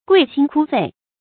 刿心刳肺 guì xīn kū fèi
刿心刳肺发音